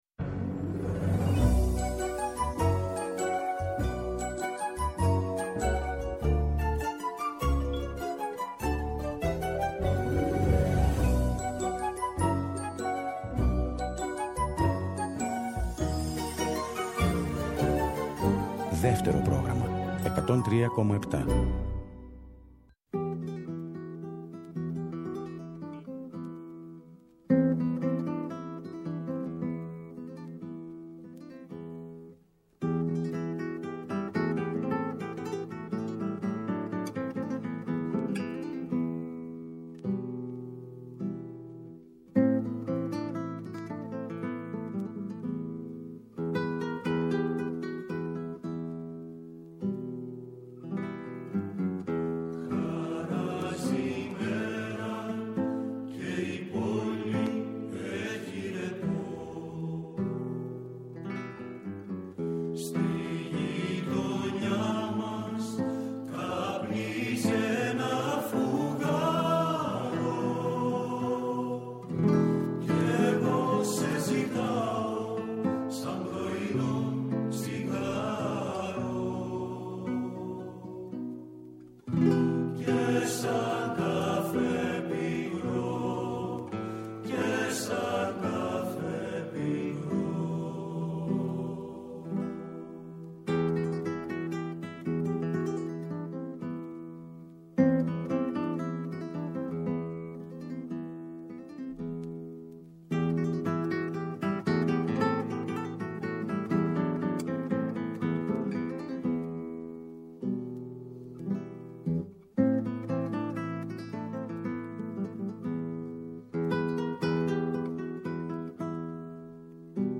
θα ακουστούν τραγούδια του συνθέτη και αποσπάσματα από κείμενά του που έχουν δημοσιευτεί στον Τύπο ή ακούστηκαν στις ραδιοφωνικές του εκπομπές